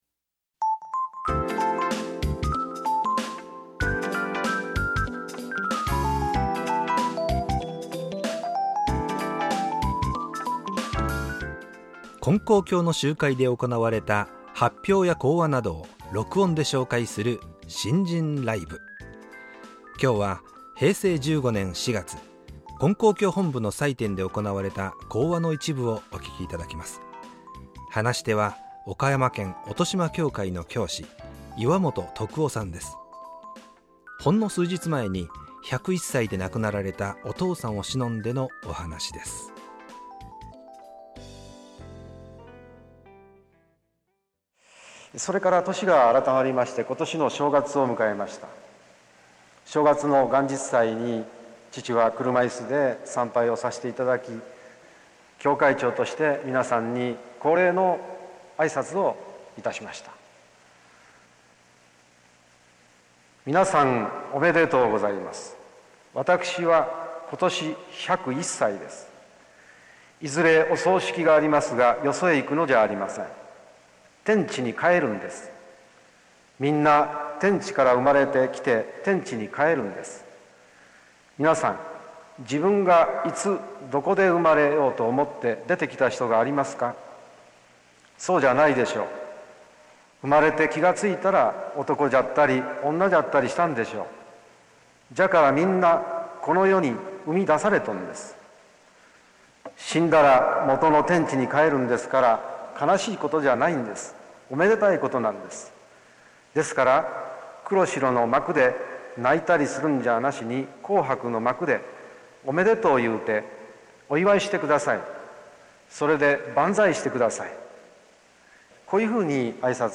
●信心ライブ